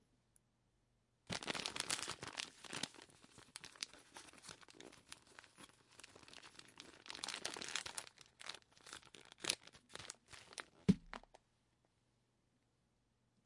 沙沙的声音 " 一个装满茶叶的纸袋 1
描述：打开充满moshi moshi茶的纸袋的声音。很多皱。
Tag: 弄皱 纸袋 茶叶 沙沙 沙沙